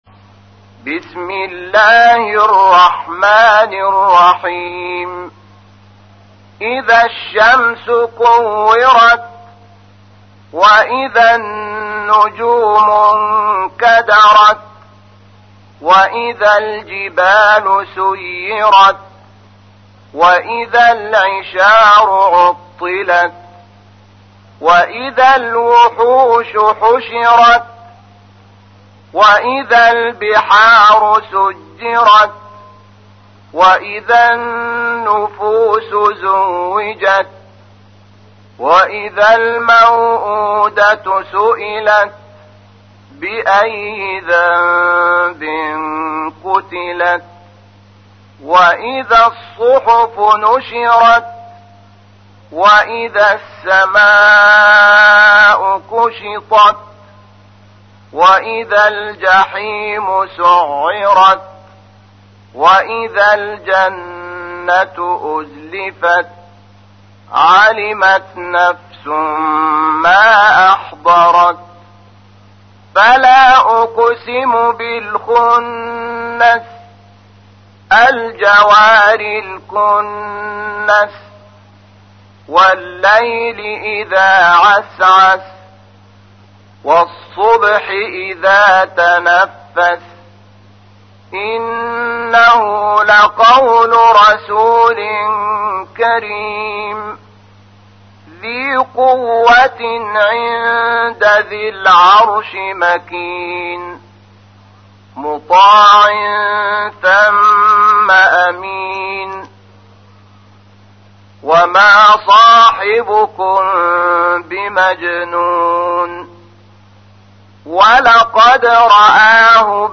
تحميل : 81. سورة التكوير / القارئ شحات محمد انور / القرآن الكريم / موقع يا حسين